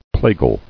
[pla·gal]